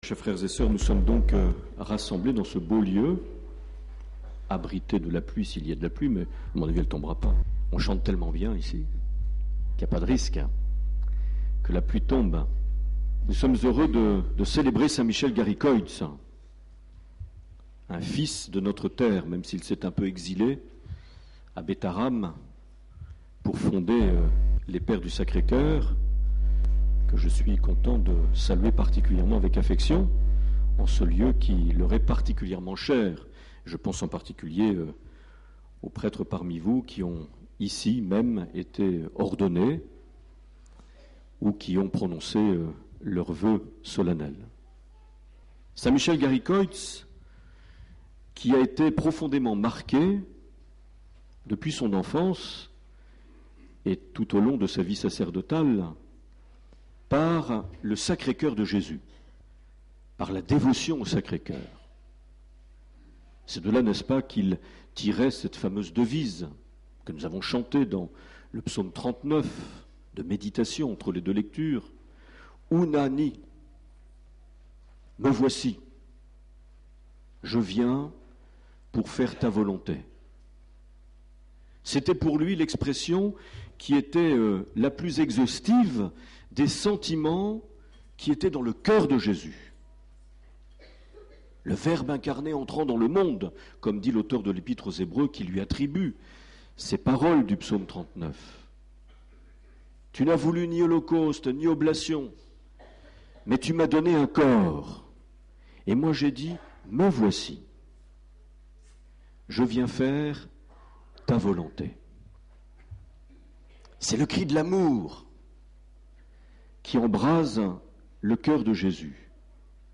31 août 2011 - Saint Just Ibarre - Pèlerinage Saint Michel Garicoïts
Une émission présentée par Monseigneur Marc Aillet